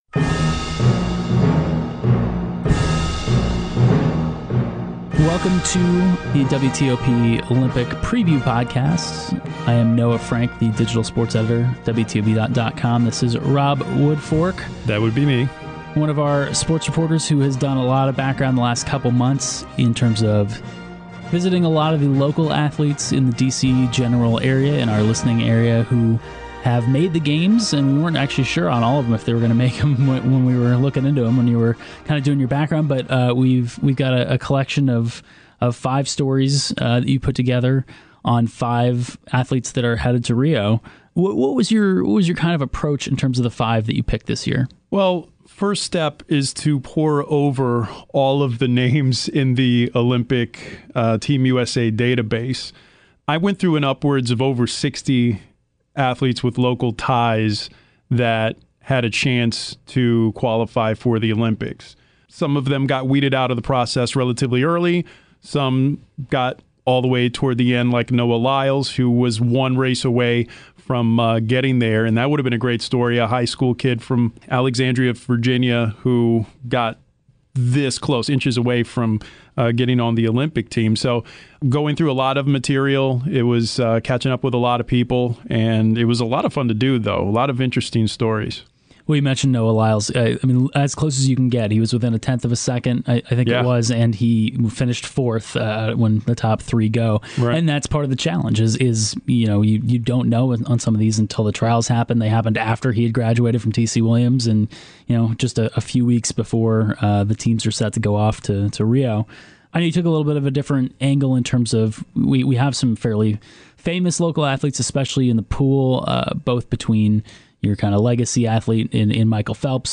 See the list of stories below, and listen to our two Olympic Preview Podcasts: the first featuring interviews with local athletes participating in the games and the second, a conversation with USA Today’s Christine Brennan about some of the larger issues surrounding the games.